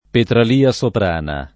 soprano [Sopr#no] agg. e s. m. e f. — come agg., usato oggi solo nei top.